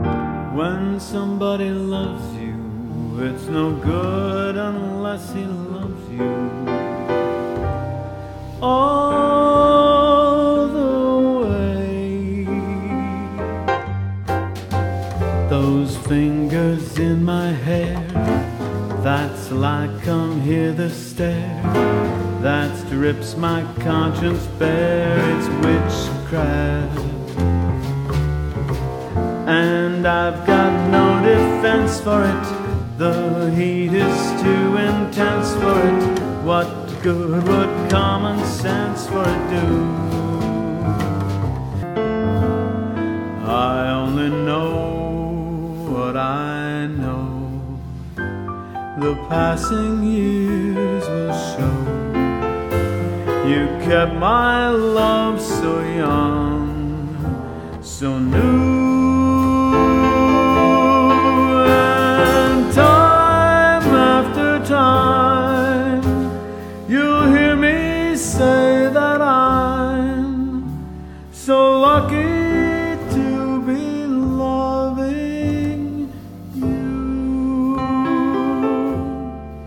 Captivating Vocalist in Jazz, Standards and Blues
Experience the magic of jazz and pop vocals.